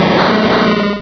Cri de Smogogo dans Pokémon Rubis et Saphir.